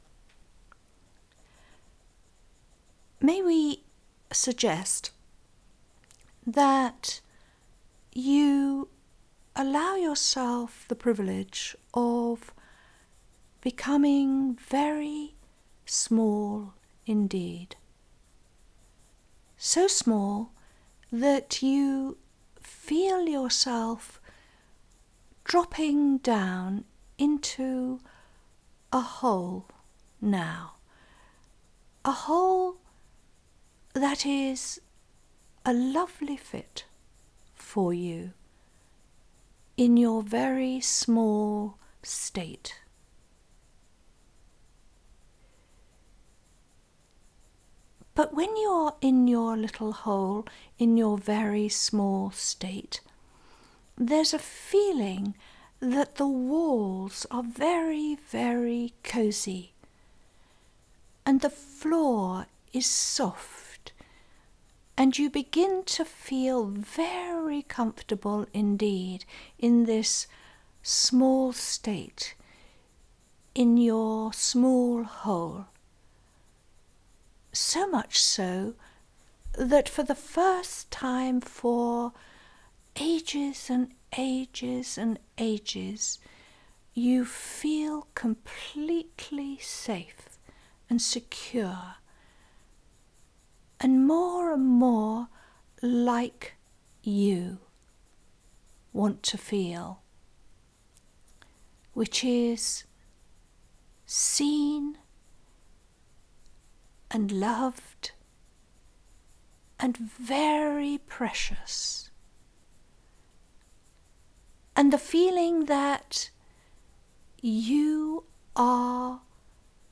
Guided audio Meditation/2